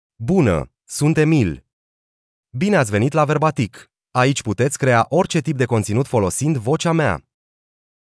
MaleRomanian (Romania)
EmilMale Romanian AI voice
Emil is a male AI voice for Romanian (Romania).
Voice sample
Emil delivers clear pronunciation with authentic Romania Romanian intonation, making your content sound professionally produced.